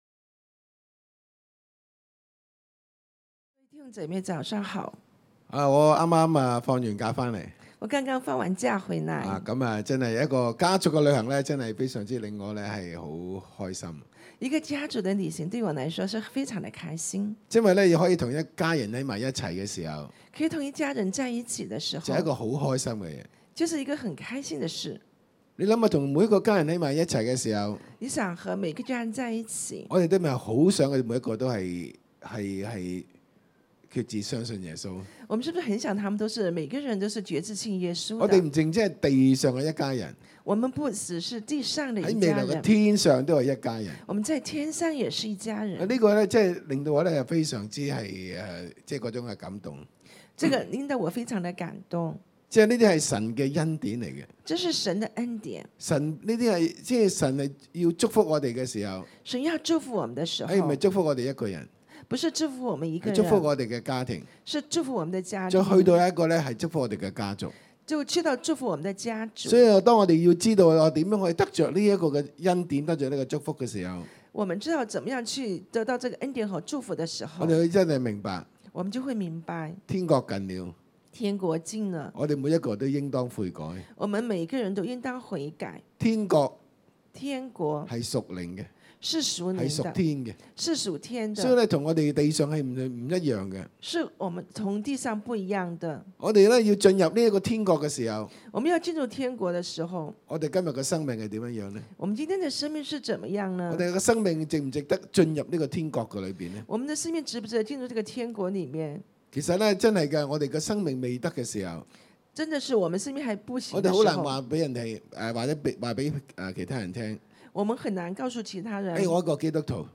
會眾並到檯前分享和數算聖靈的工作。